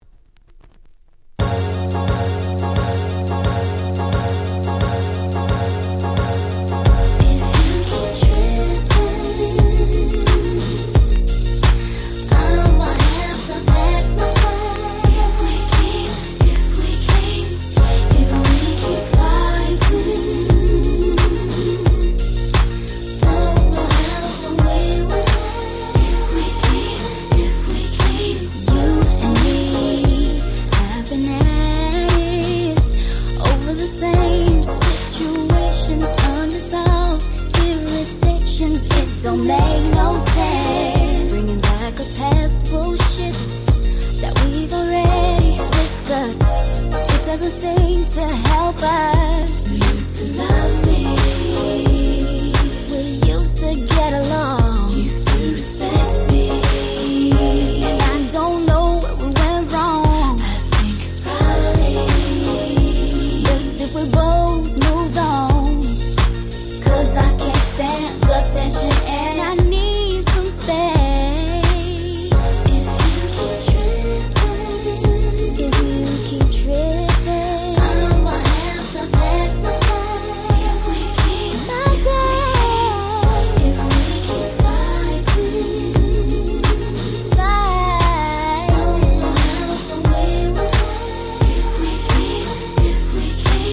03’マイナーR&B良作！！